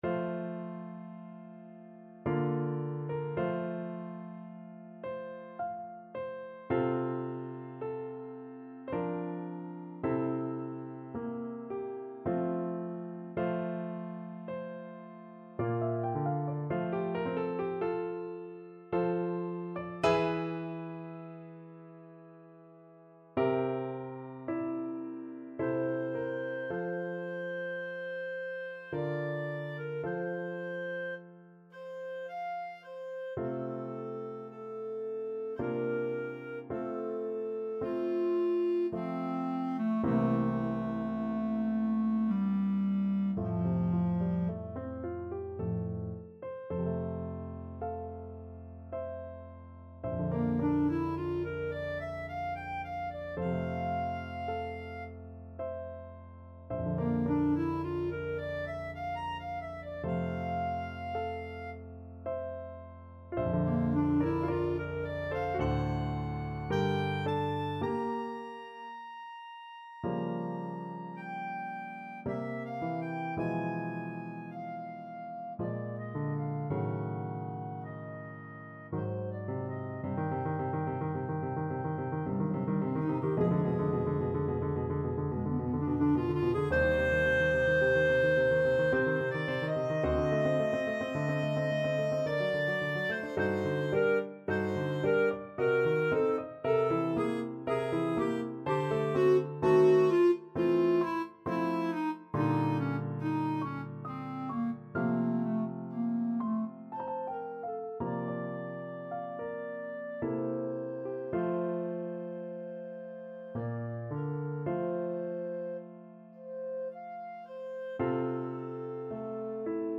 Clarinet
F major (Sounding Pitch) G major (Clarinet in Bb) (View more F major Music for Clarinet )
3/4 (View more 3/4 Music)
Adagio ma non troppo =108
Classical (View more Classical Clarinet Music)